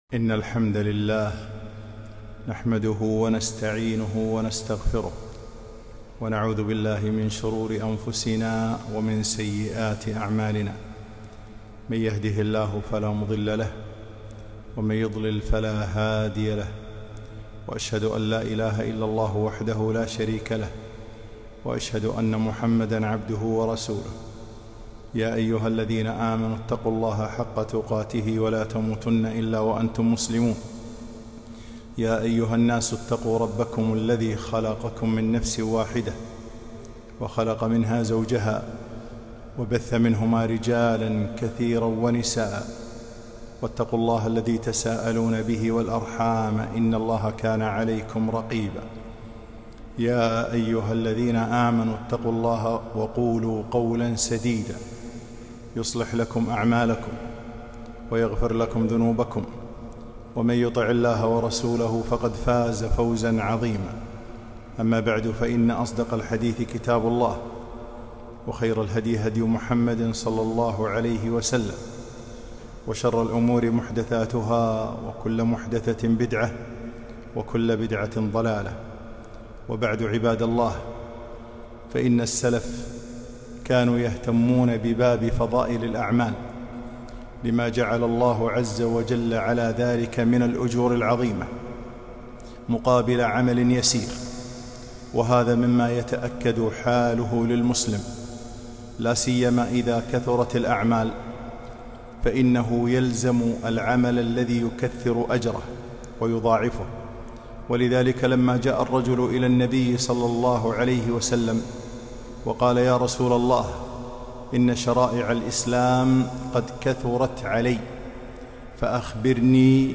خطبة - الترغيب في فضائل الأعمال